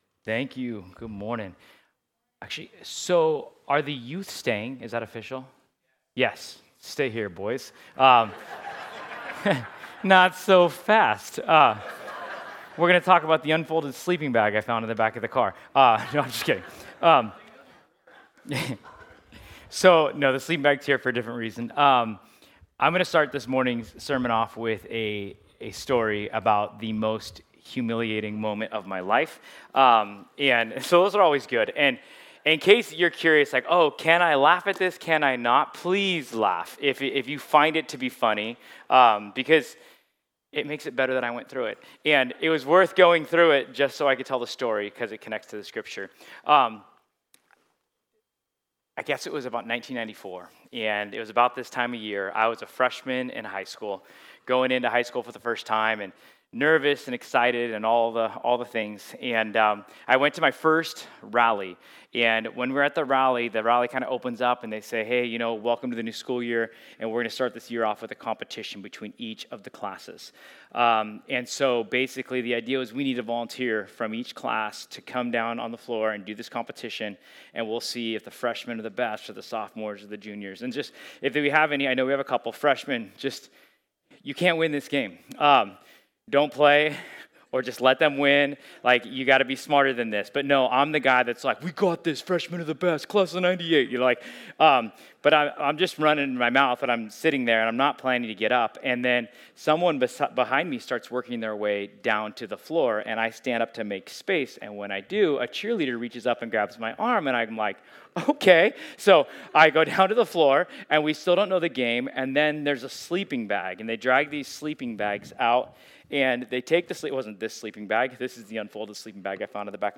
Ephesians 4:17-31 Service Type: Sunday Change isn’t easy—but it’s essential.